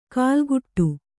♪ kālguṭṭu